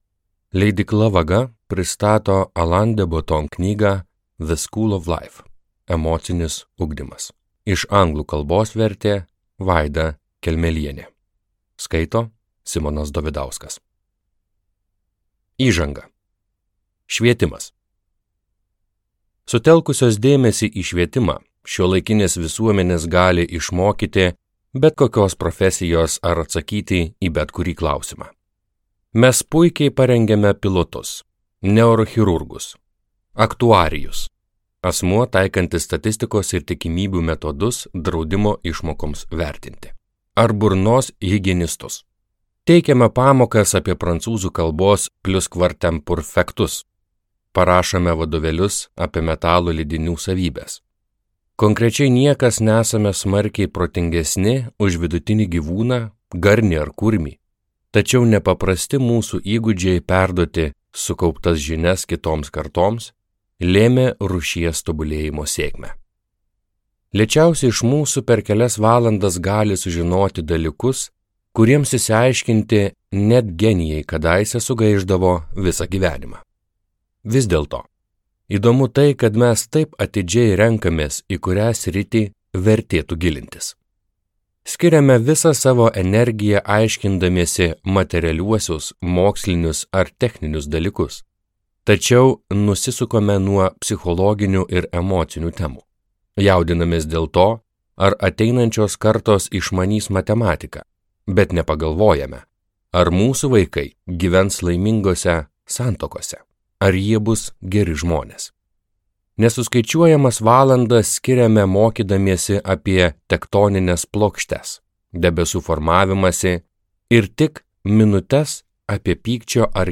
Emocinis ugdymas | Audioknygos | baltos lankos